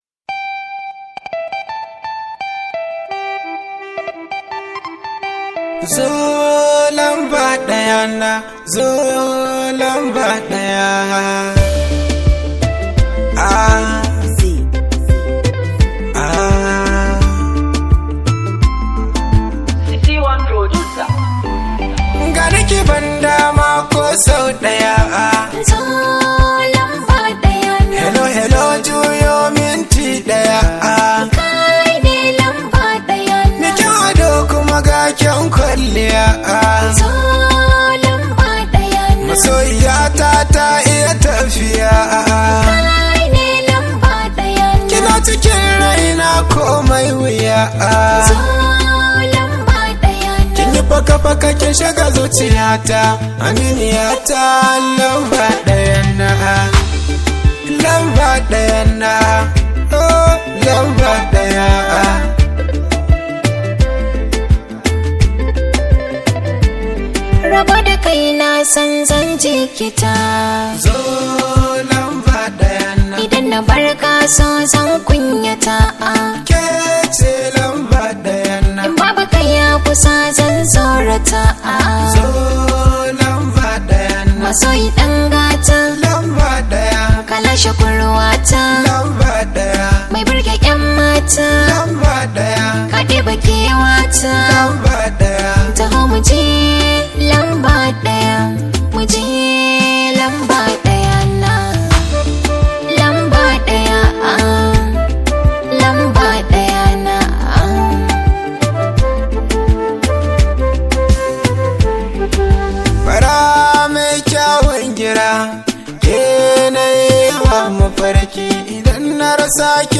Hausa Songs